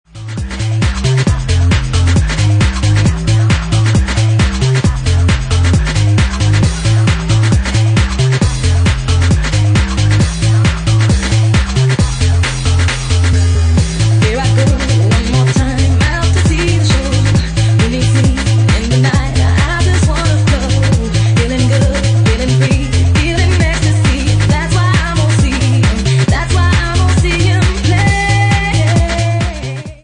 Genre:Bassline House
Bassline House at 136 bpm